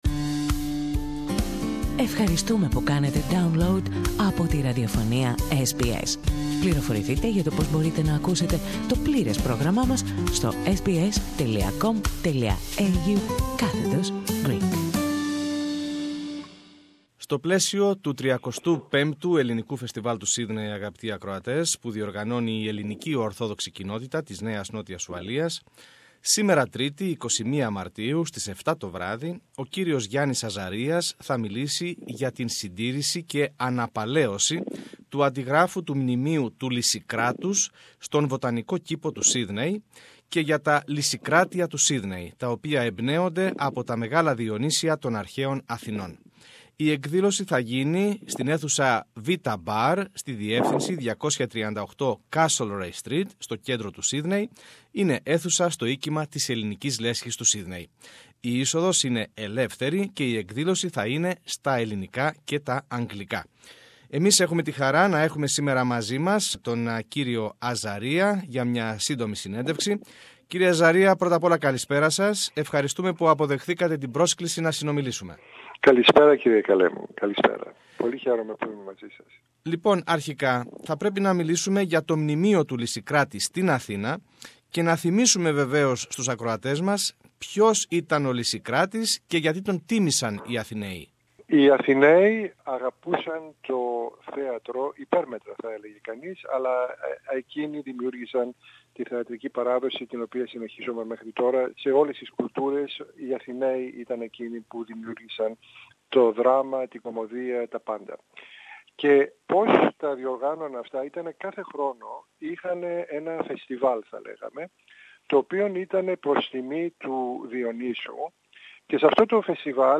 The Lysicrates Prize: Linking Greece and Australia: A talk